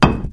concrete1.wav